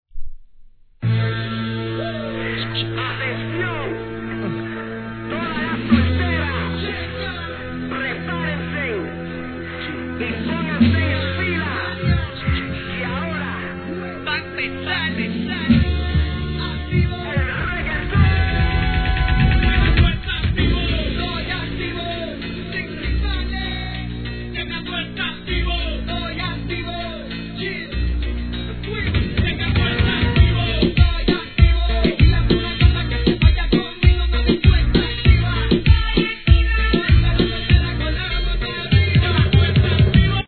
1. HIP HOP/R&B
■REGGAETON